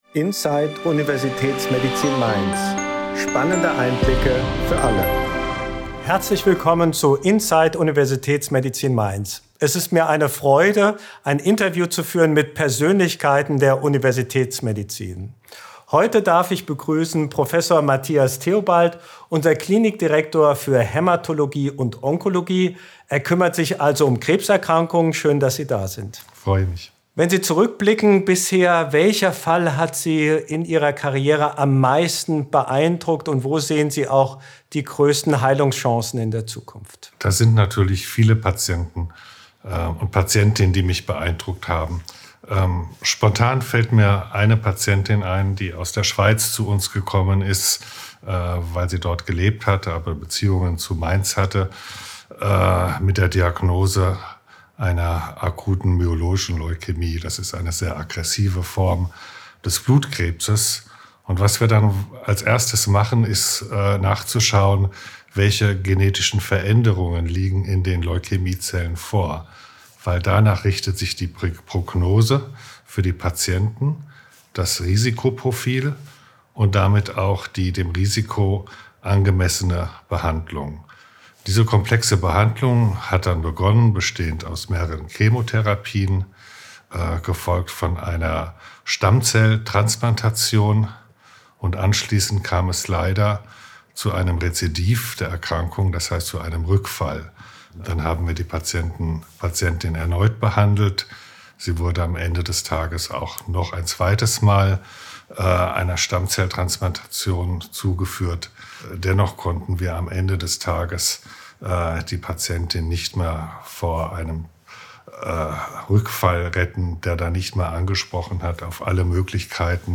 Wenn dies doch der Fall ist, arbeiten unter dem Dach des Onkologischen Spitzenzentrums der Universitätsmedizin Mainz zahlreiche Spezialistinnen und Spezialisten gemeinsam daran, dass die Erkrankung nicht schicksalhaft ist, sondern dass Patient:innen die bestmögliche, individuelle Therapie bekommen. Auch eine mögliche künftige Krebsimpfung steht im Fokus des Gesprächs.